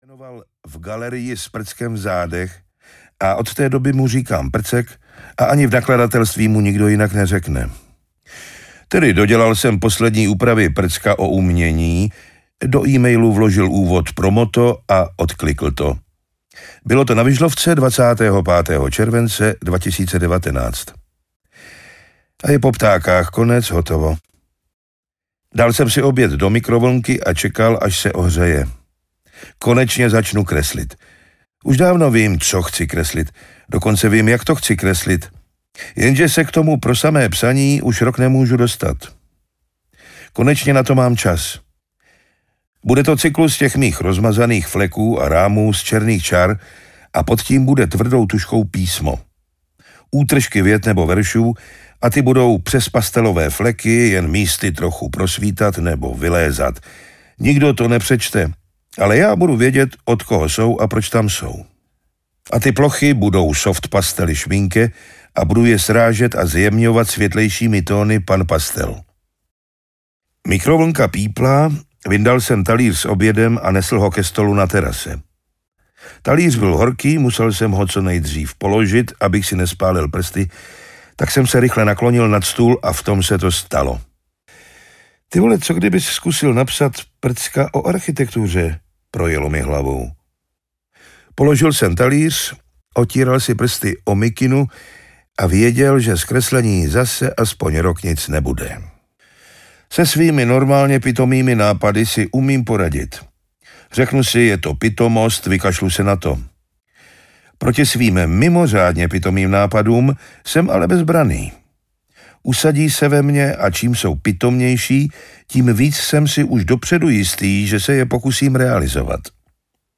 Hodně jsem nikde audiokniha
Ukázka z knihy